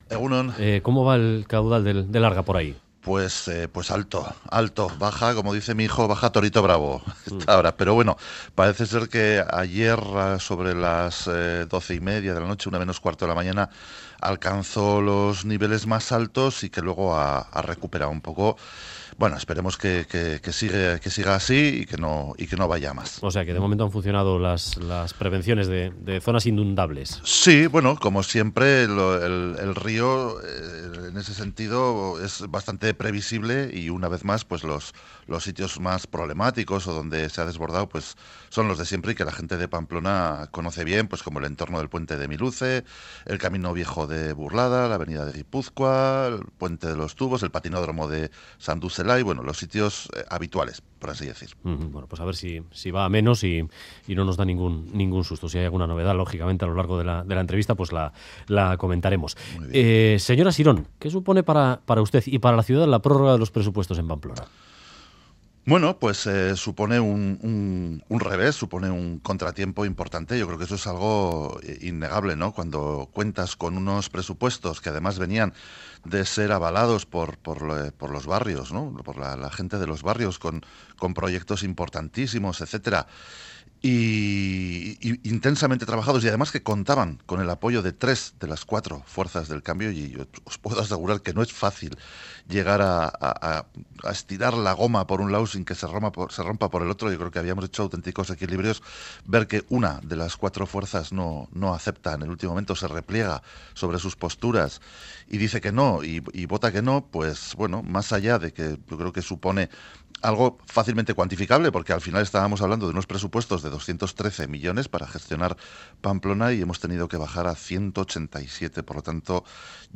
Audio: El alcalde de Pamplona insiste en que en el caso del Gaztetxe Maravillas lo importante no es la ubicación es el proyecto de autogestión elecciones plebiscito